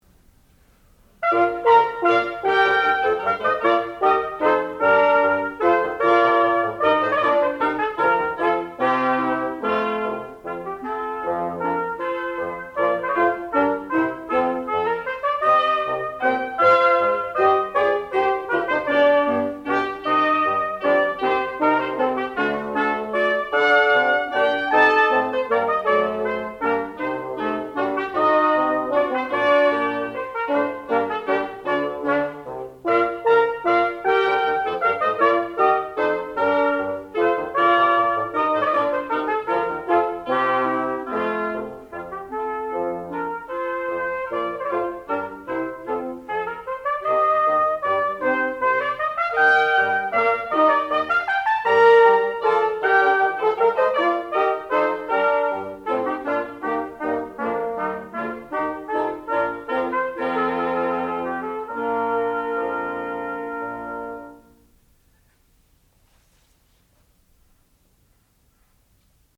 sound recording-musical
classical music
oboe
clarinet
flute